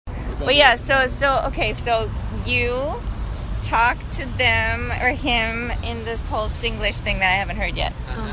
TEMP: Singlish 2
UESinglish-2.mp3